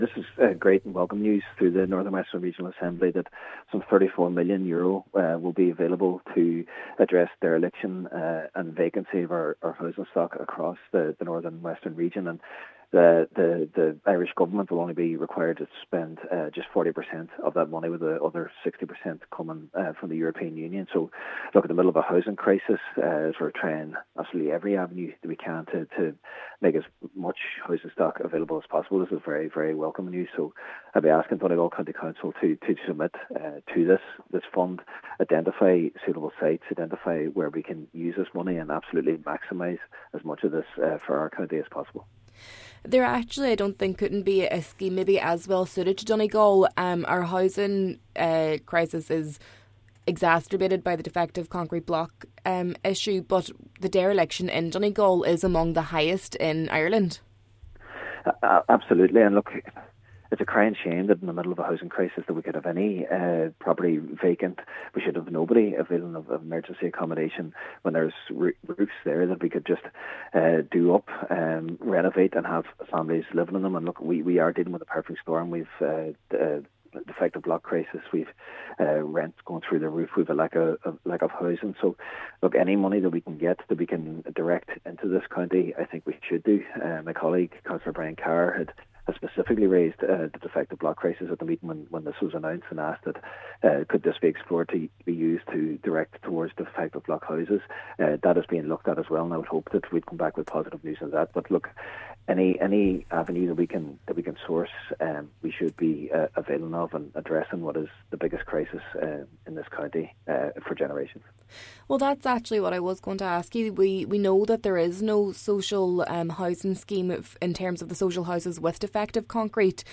Cllr Murray says in the absence of a dedicated scheme for social housing, this would be a step in the right direction: